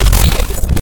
e_glitched.ogg